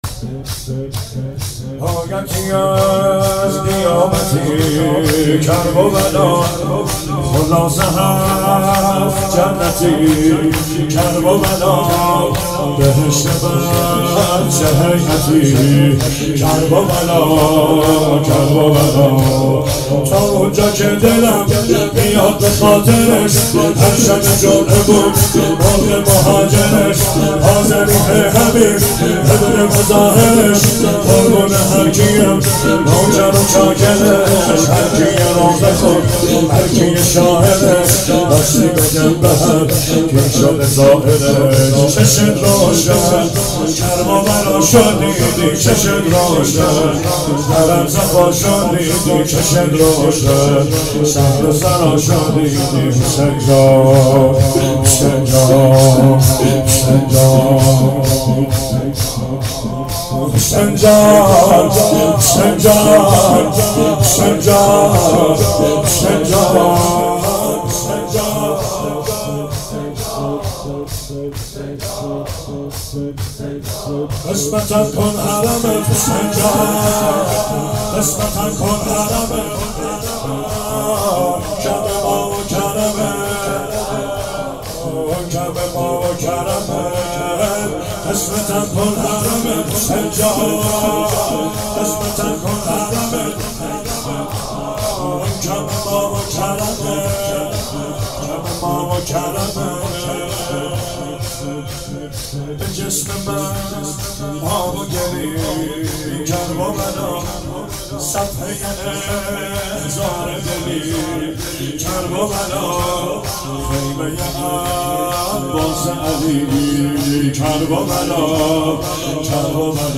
مناسبت : شب هشتم محرم
قالب : شور